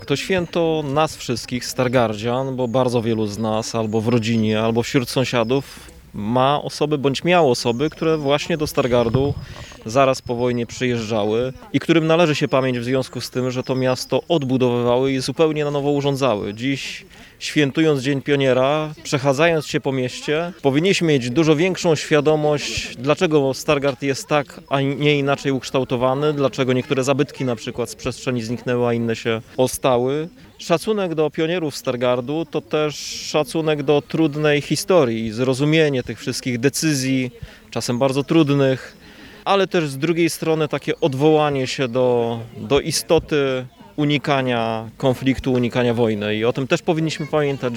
Z tej okazji w Parku Chrobrego w Stargardzie została odsłonięta wystawa Wieści z „Wieści”.